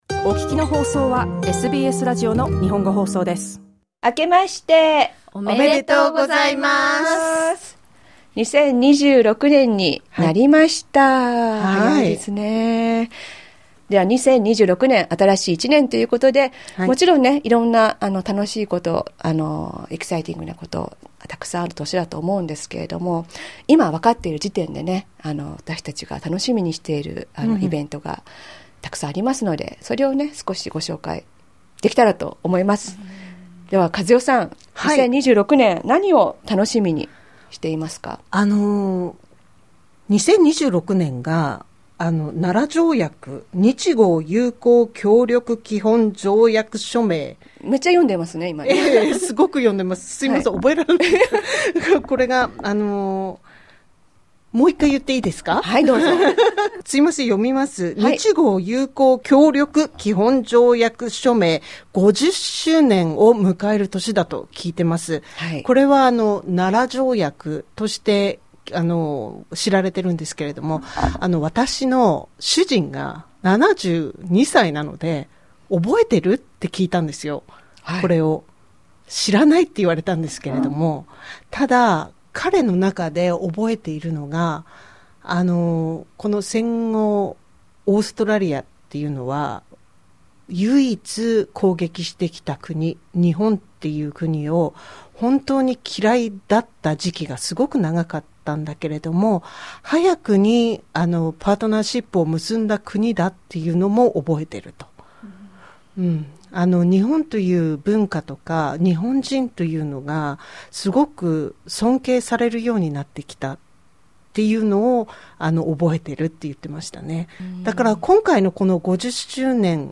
今年楽しみにしていることについて、シドニーチームによるカジュアルなトークです。